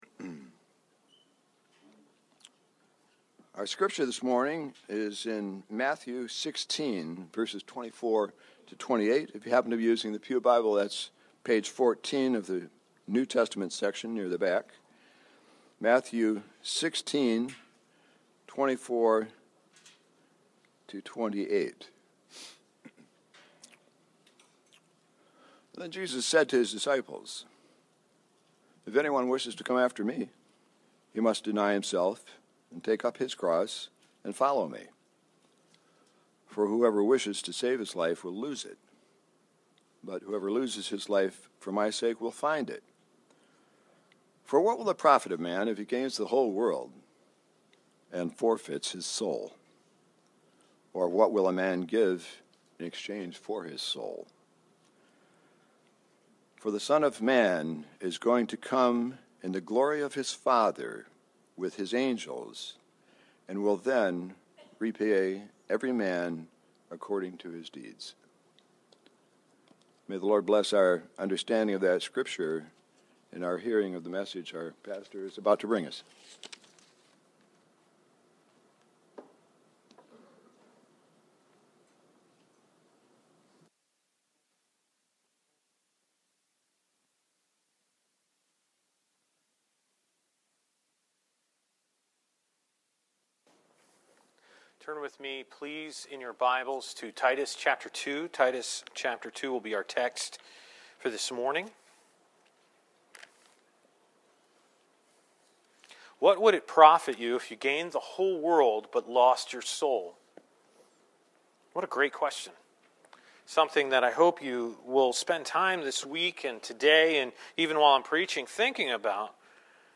Immanuel Bible Church: Sermon Audio » Stand Alone Sermons